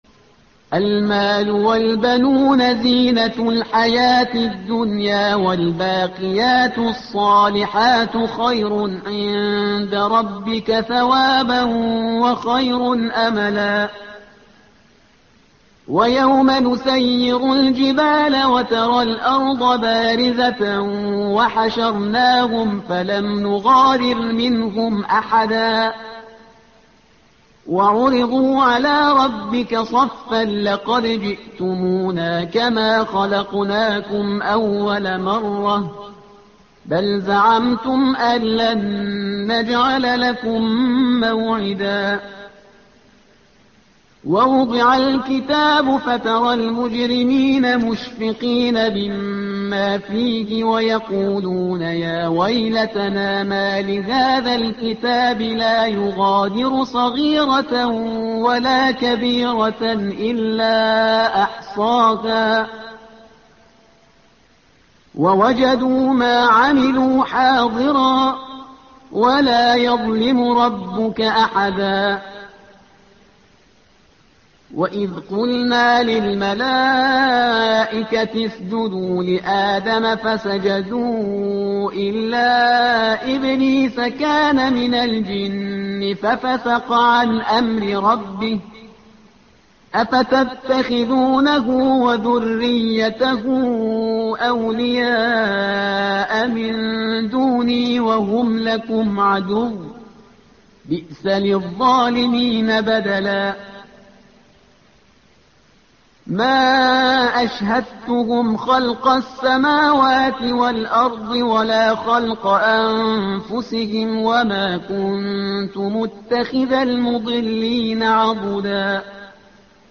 الصفحة رقم 299 / القارئ